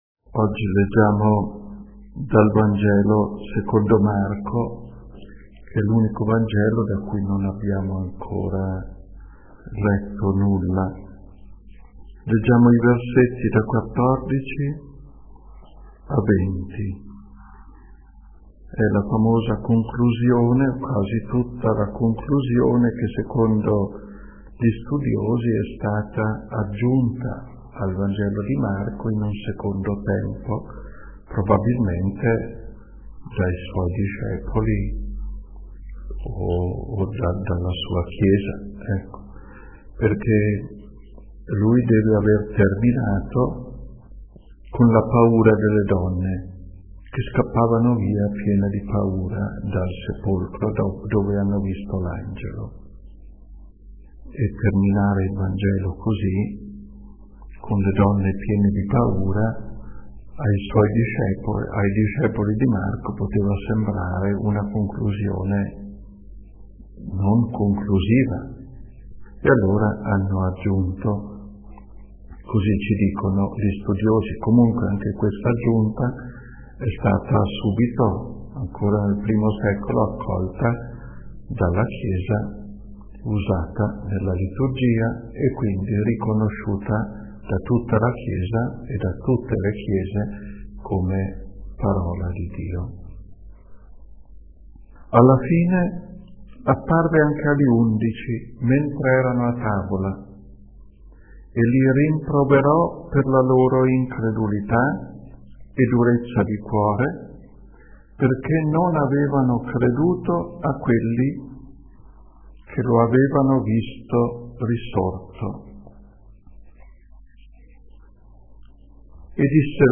* Meditazioni audio degli Esercizi Spirituali sulla fede: Testimoni del Risorto, tenuti nel 2013 (in formato mp3).